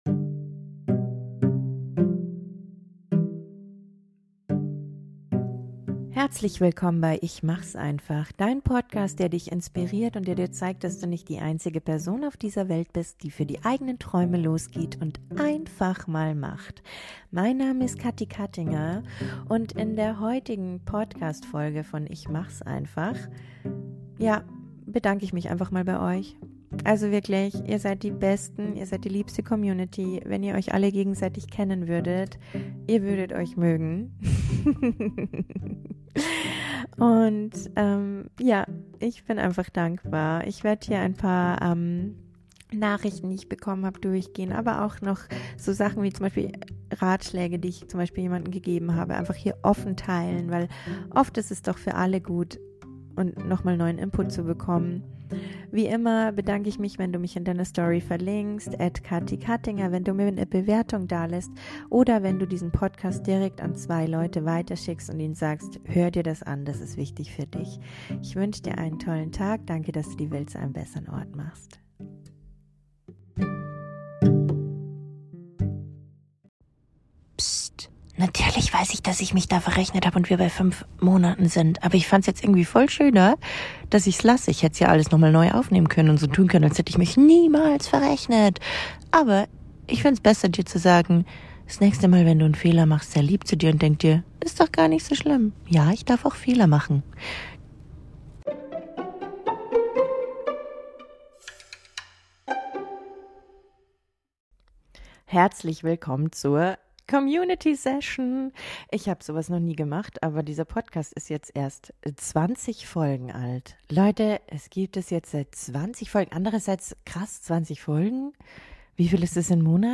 Um Dir ein Bild davon zu machen, was wir Größen hier diesen Podcast hören, lese ich dir ein paar Nachrichten von Hörer*innen vor. Du merkst, dass Du mit Deinen Struggle nicht alleine bist und vor allem, wie großartig Du bist!